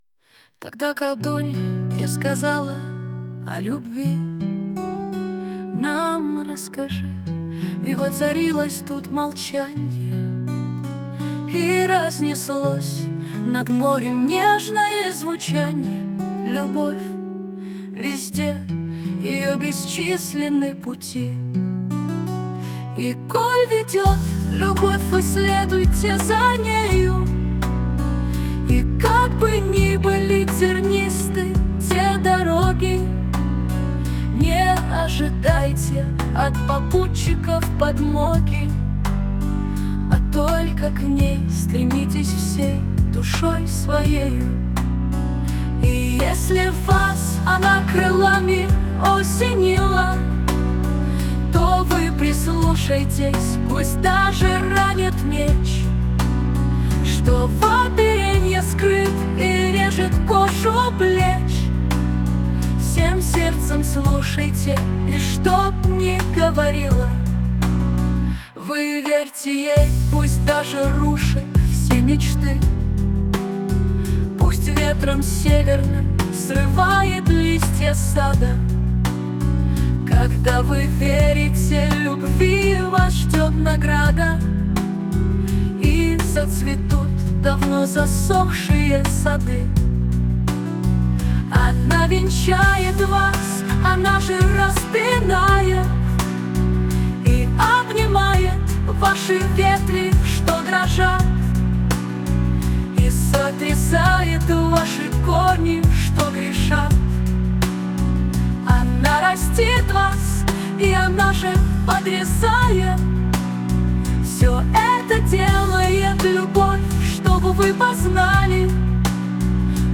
Песня озвучена с помощью ИИ